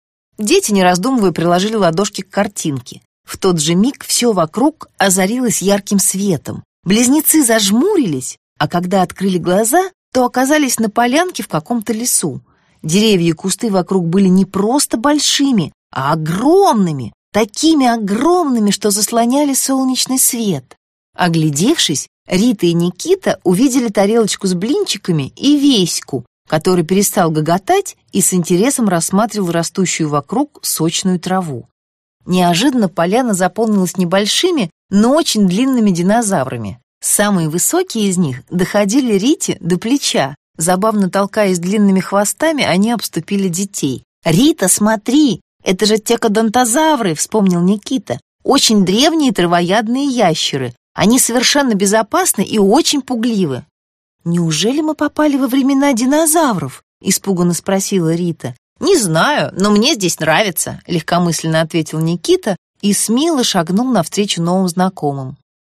Аудиокнига В гостях у динозавров. Путешествие в триасовый период | Библиотека аудиокниг